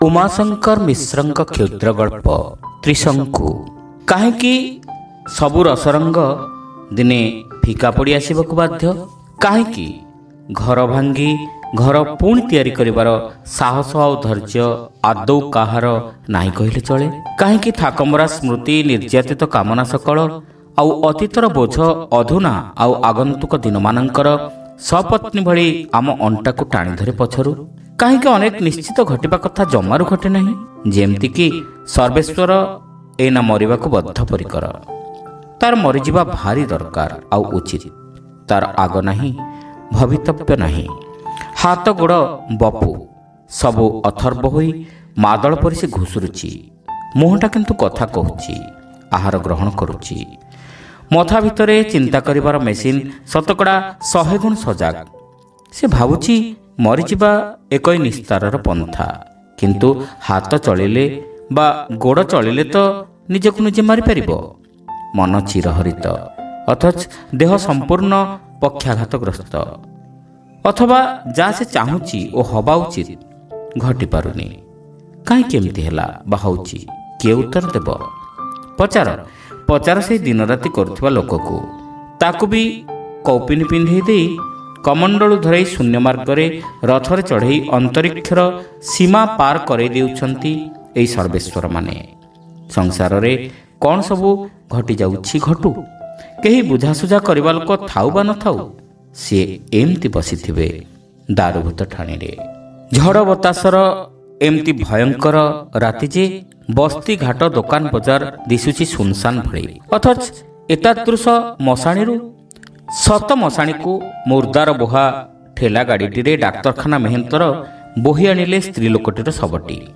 Audio Story : Trishanku (Part-1)